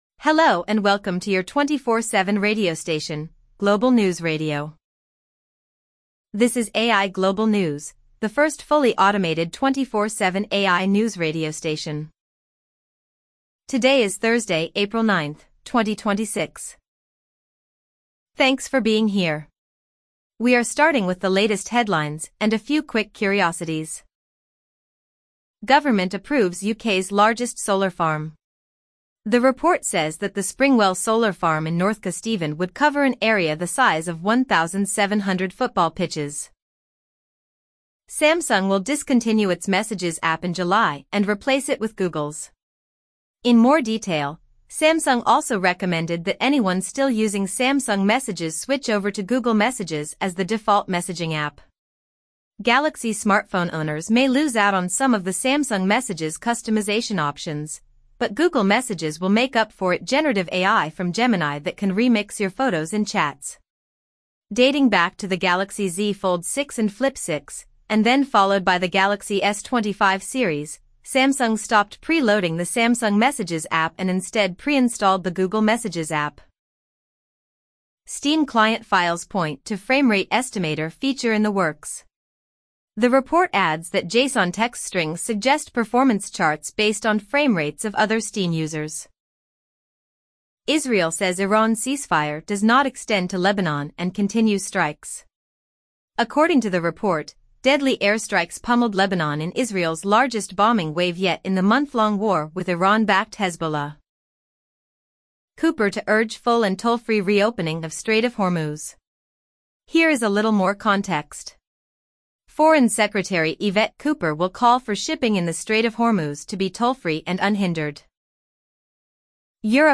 Streaming a free automated newsroom with fresh AI bulletins, continuous updates, and a cleaner on-air feel.